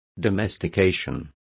Complete with pronunciation of the translation of domestication.